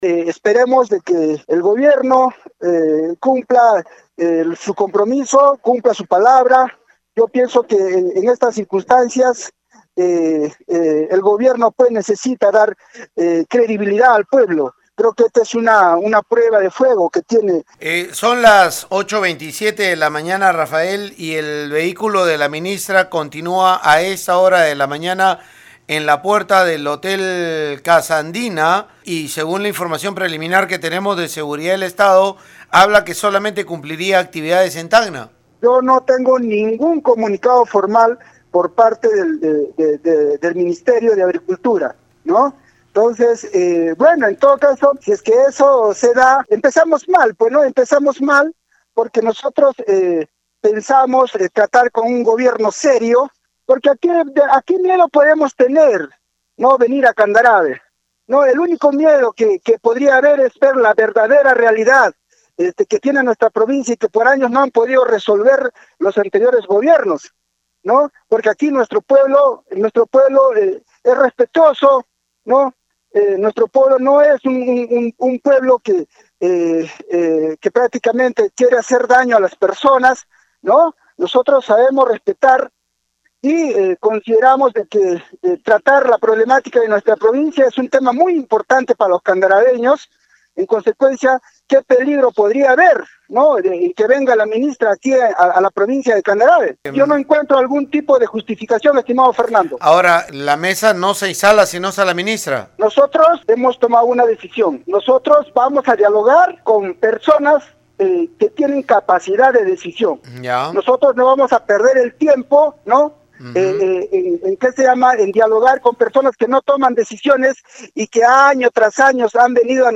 rrafael-vega-alcalde-de-candarave.mp3